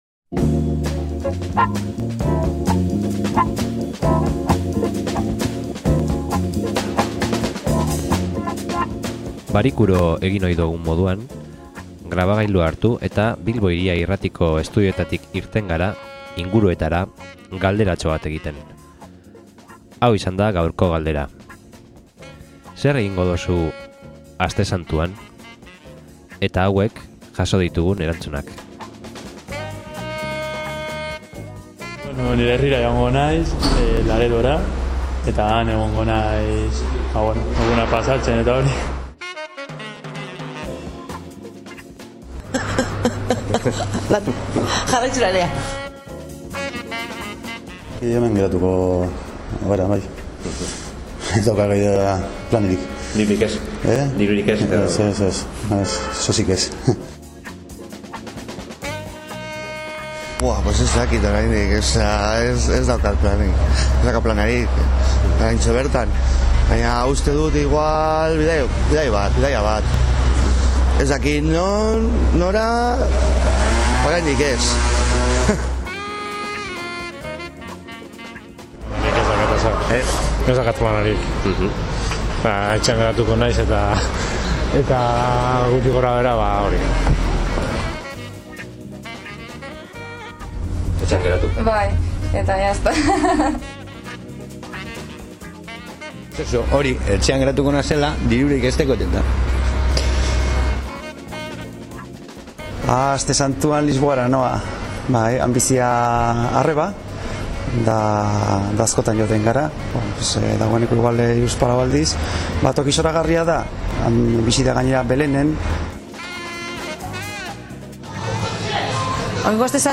INKESTA
solasaldia